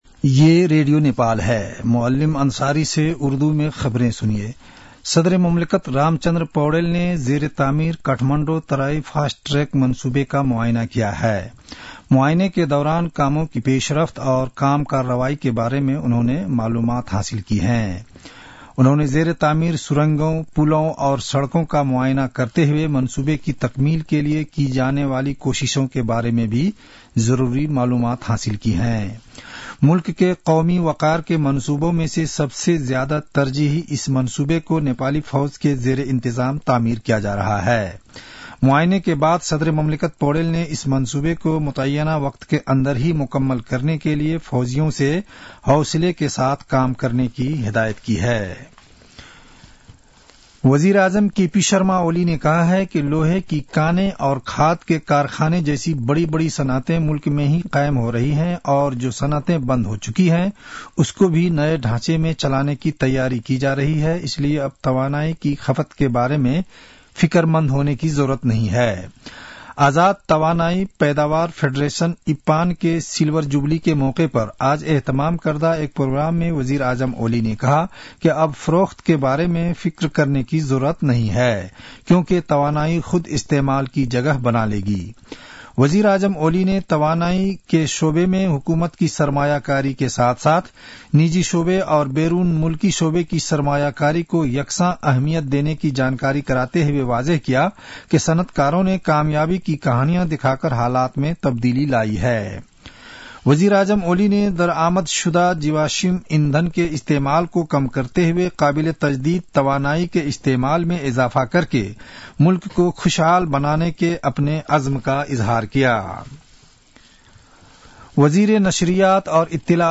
उर्दु भाषामा समाचार : ५ माघ , २०८१